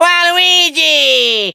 18 KB {{aboutfile |1=Waluigi exclaims his name from ''Super Mario Party Jamboree''.